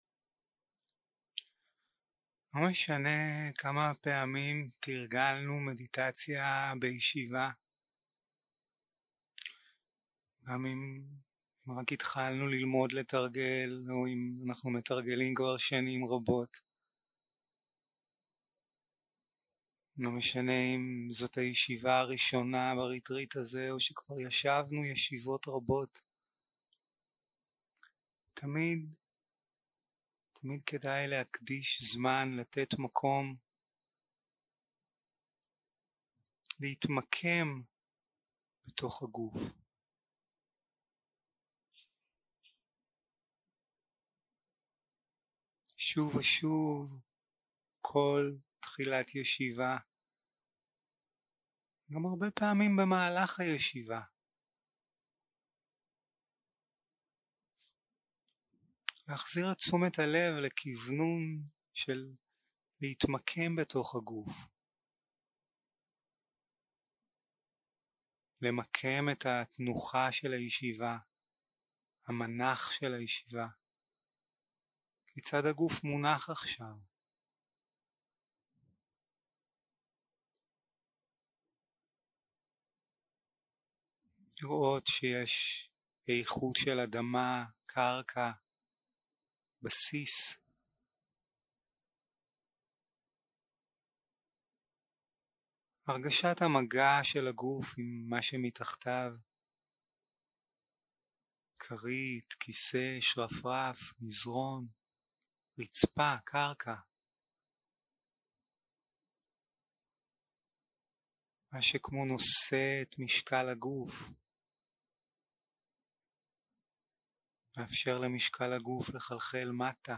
יום 4 - הקלטה 9 - צהרים - מדיטציה מונחית